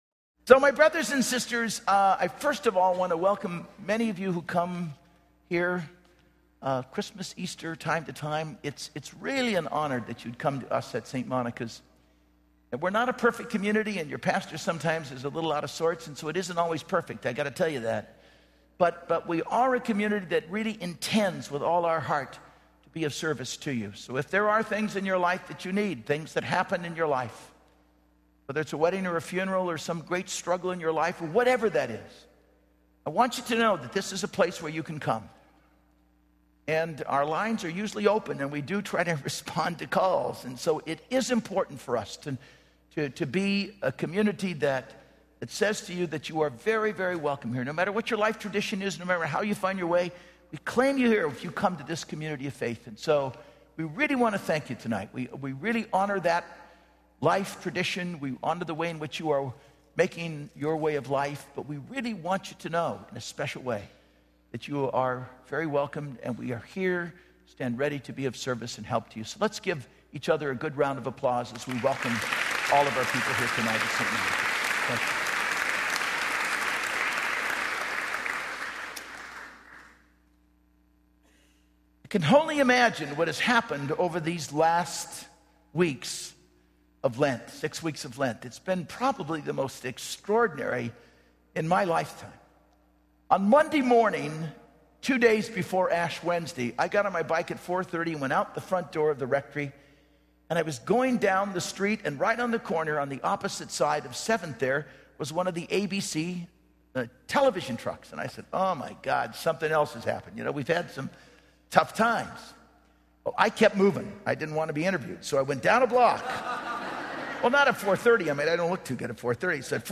Homily & Song - 3/31/13 - Easter Sunday
Soloists